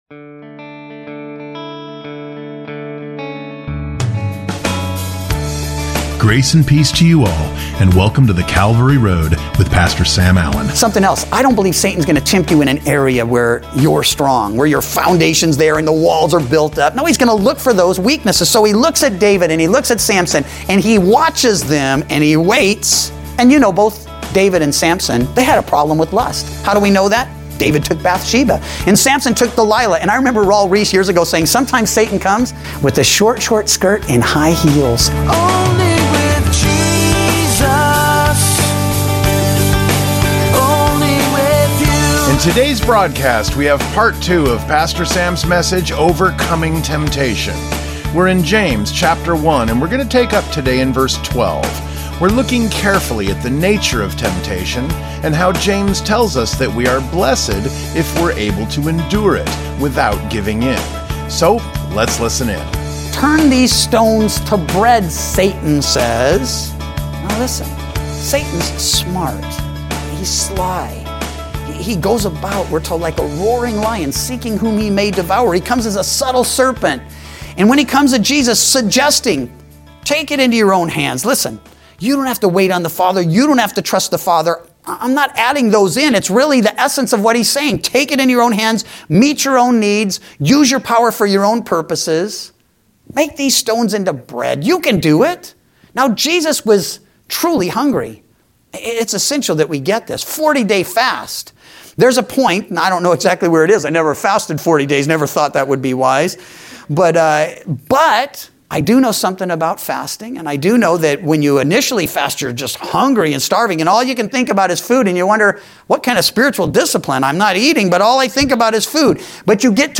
Weekday Radio Program